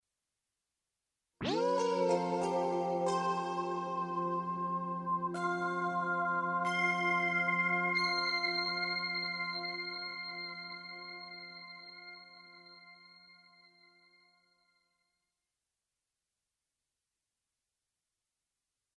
80s Logo music with tape delay
I created this old movie logo style melody for the opening of a song I wrote. It uses the Satin Tape delay to achieve the tape speed jump at the start. It's done by modulating the distance of the tape head whilst set in 'delay' mode.